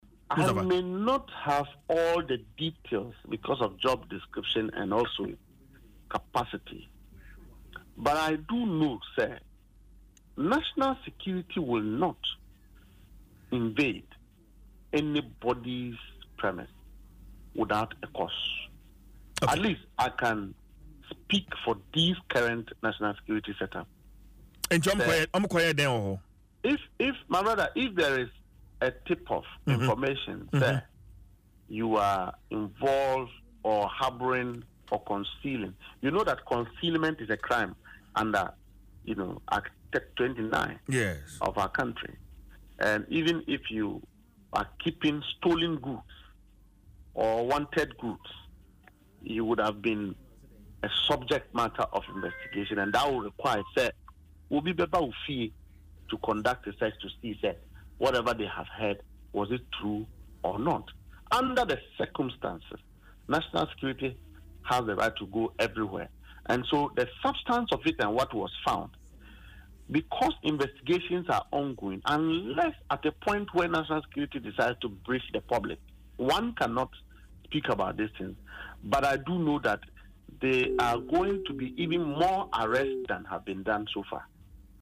In an interview on Adom FM’s Dwaso Nsem, Mustapha emphasized that National Security operatives would not invade individuals’ homes without a valid reason.